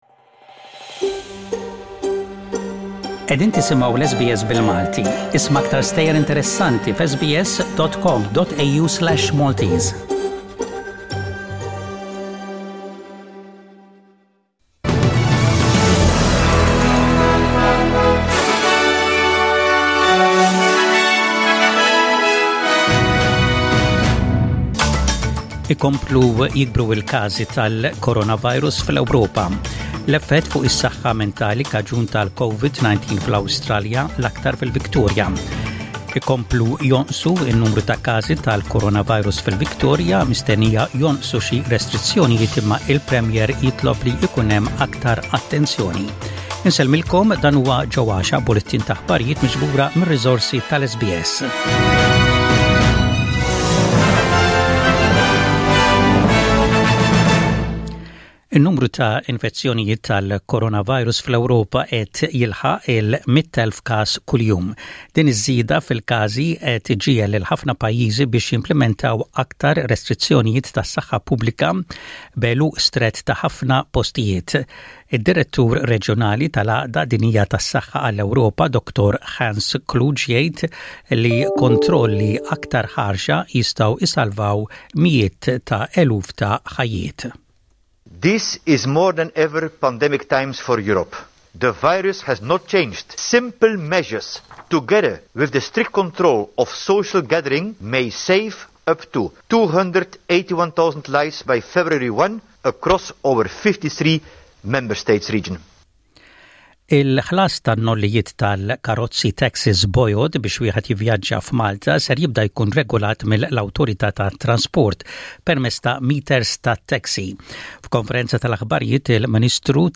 SBS Radio | Maltese News 16/10/20